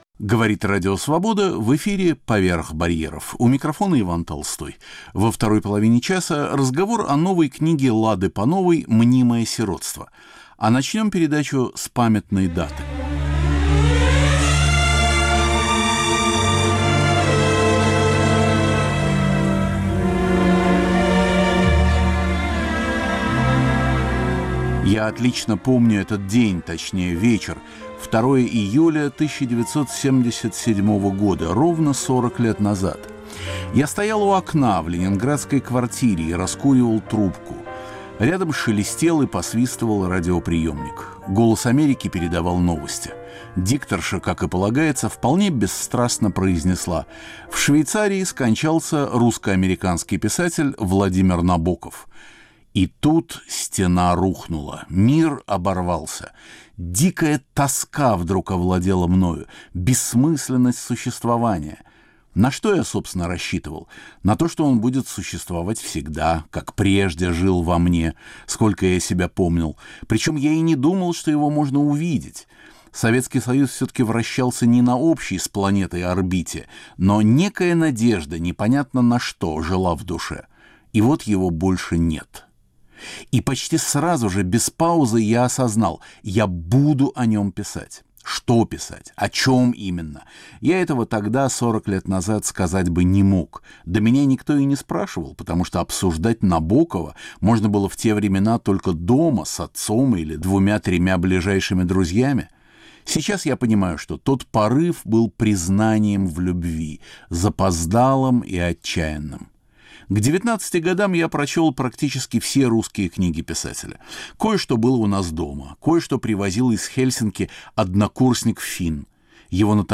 беседа о наследии русских футуристов